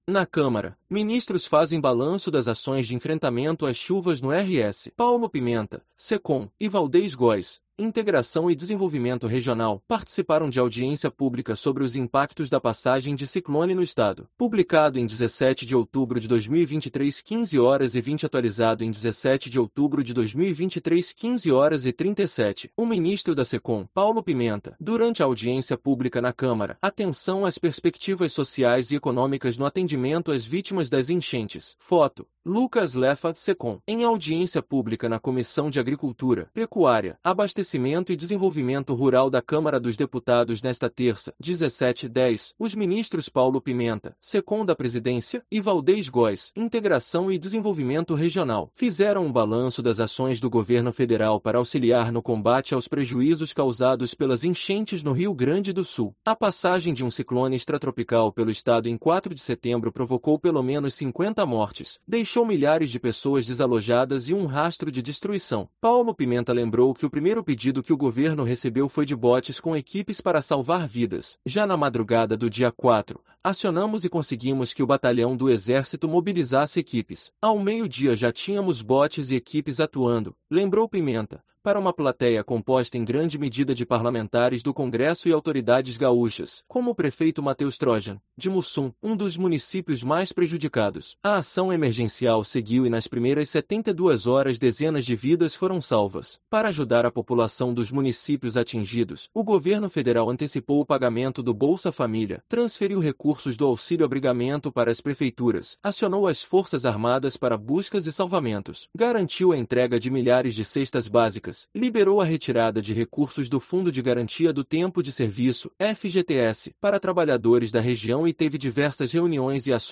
Paulo Pimenta (Secom) e Waldez Góes (Integração e Desenvolvimento Regional) participaram de audiência pública sobre os impactos da passagem de ciclone no estado